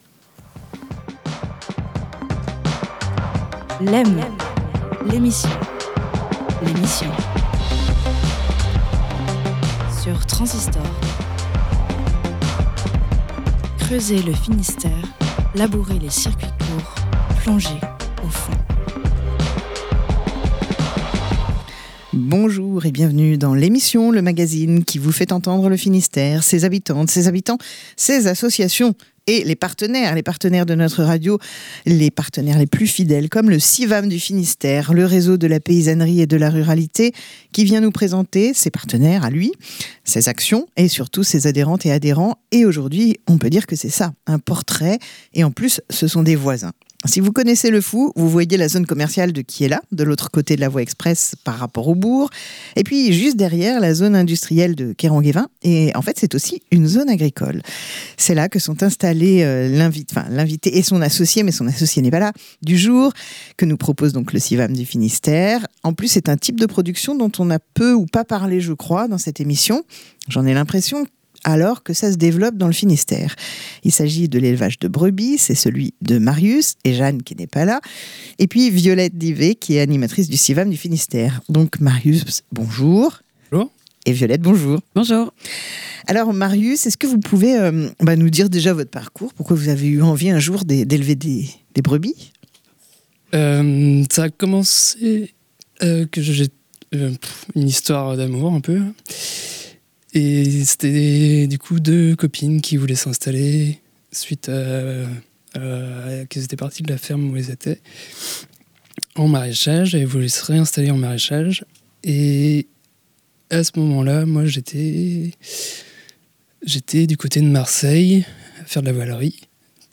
Une émission mensuelle de Lem, la quotidienne, réalisée en partenariat avec le réseau Civam du Finistère